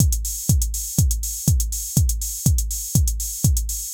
AIR Beat - Mix 6.wav